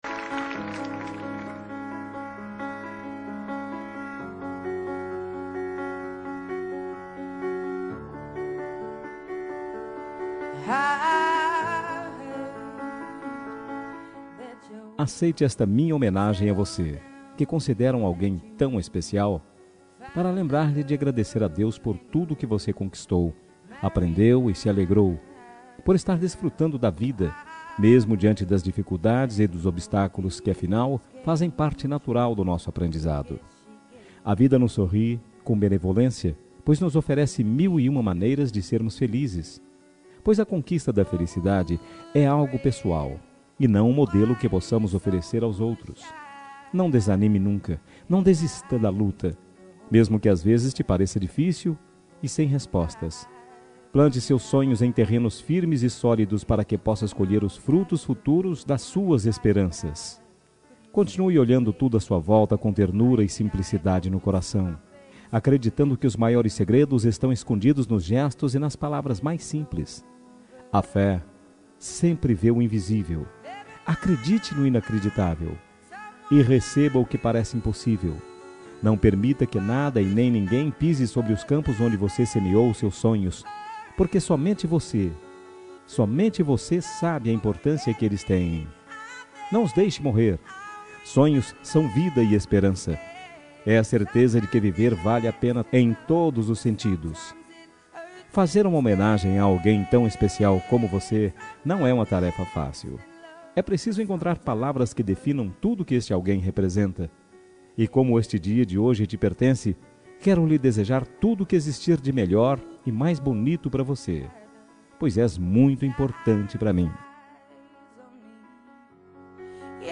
Telemensagem Você é Especial – Voz Masculina – Cód: 5424